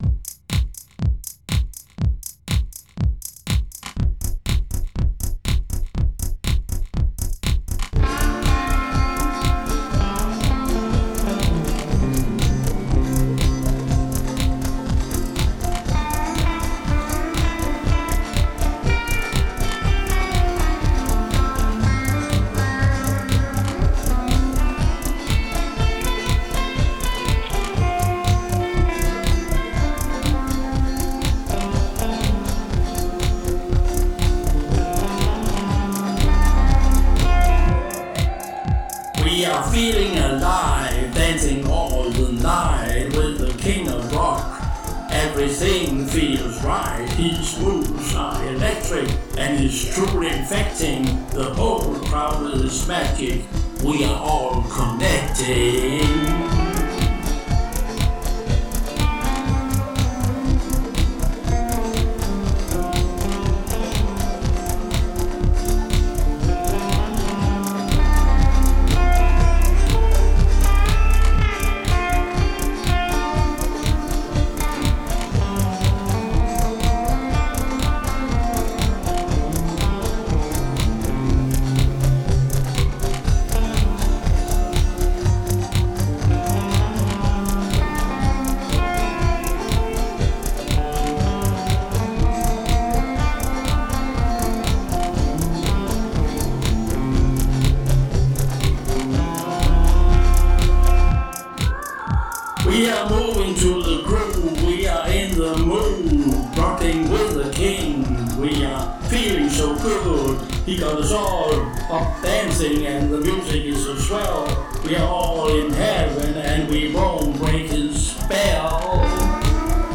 Genre: Electro Pop